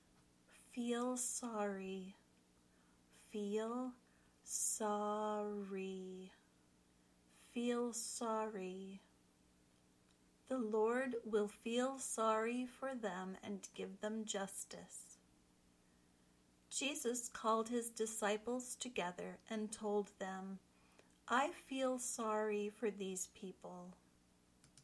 Then, listen to how it is used in the sample sentences.
fiːl ˈsɑː ri  (idiom)